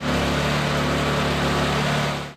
techage_generator.ogg